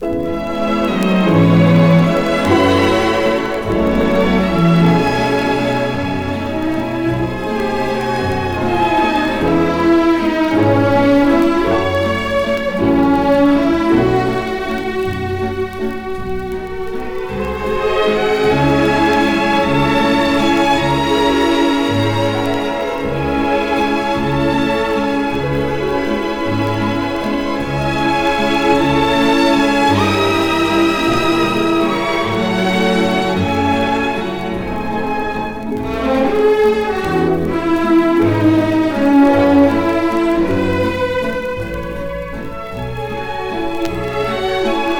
美しい旋律と優しいムードに包まれる爽やかな良盤です。